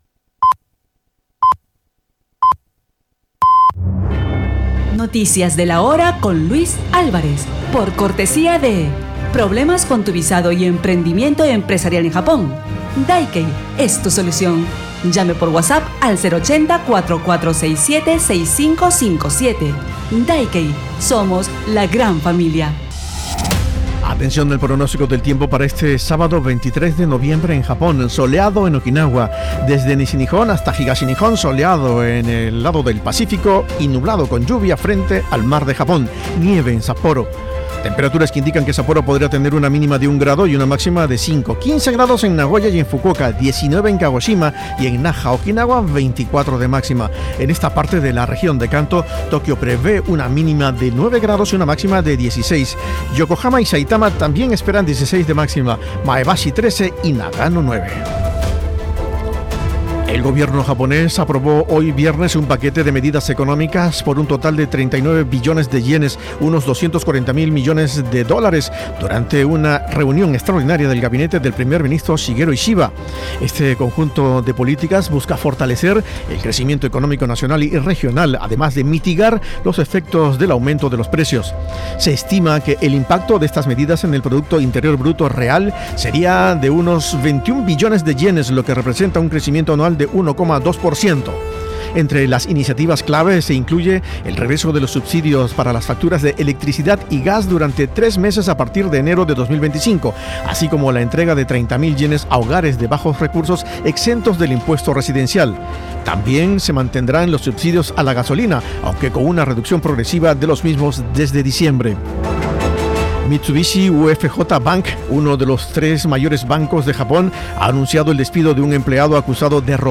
Podcast de noticias de Japón por cortesía de DAIKEI, expertos en inmigración y asesoría empresarial.